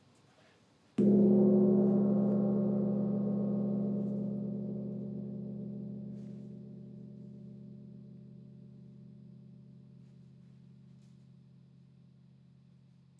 Petit_doucement_haut.wav